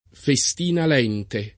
[lat. fe S t & na l $ nte ; non f $S - … ]